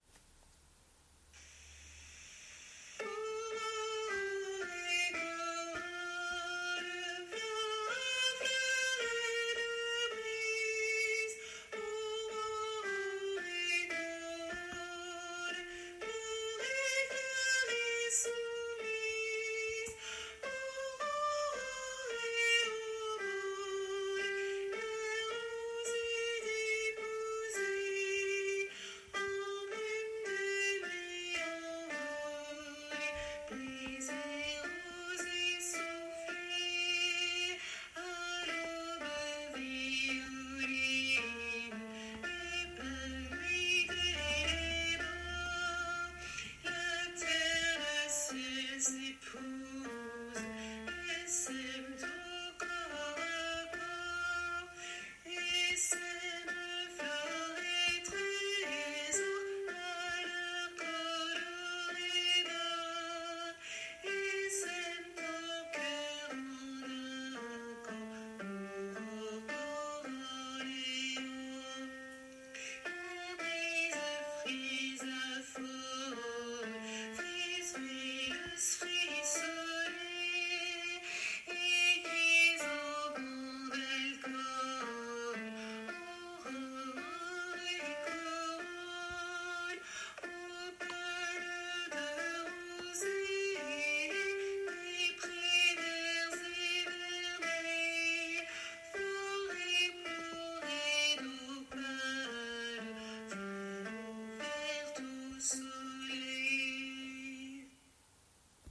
- Oeuvre pour choeur à 4 voix mixtes (SATB)
MP3 versions chantées
Alto